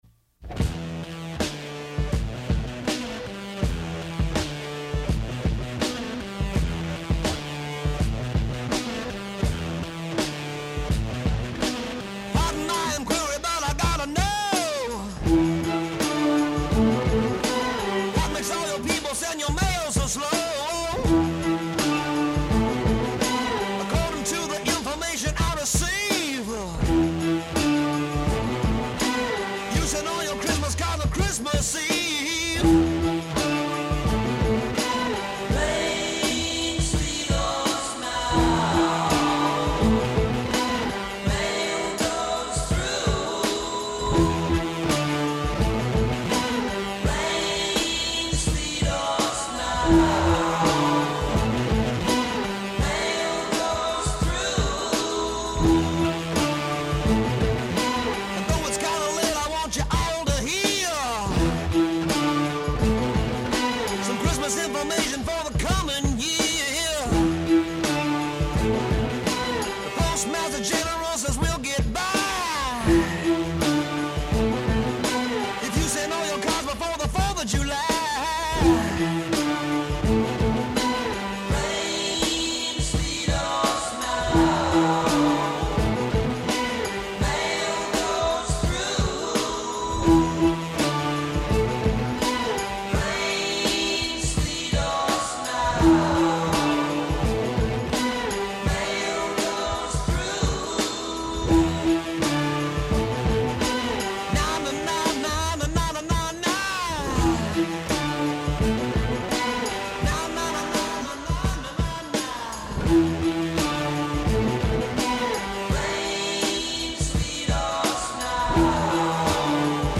bizarre psychedelic rock